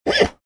На этой странице собрана коллекция натуральных звуков ржания лошадей.
Звук жеребенка